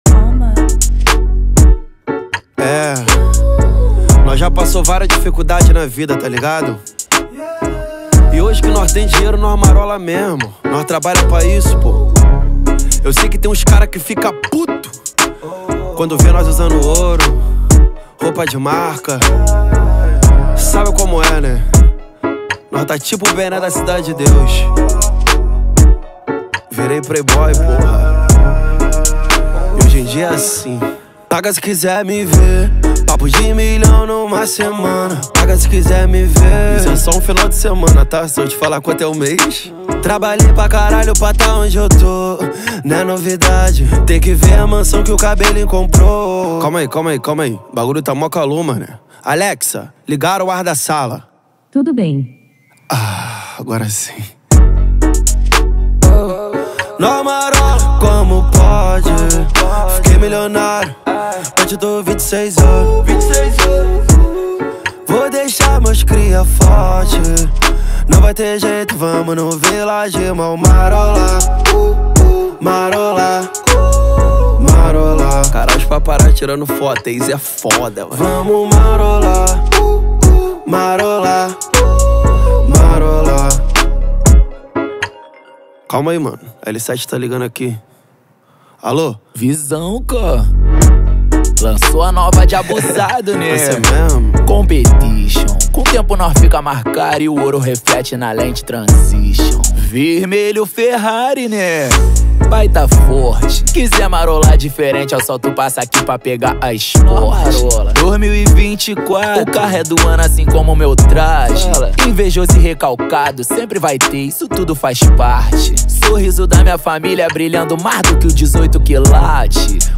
2024-06-10 16:10:56 Gênero: Funk Views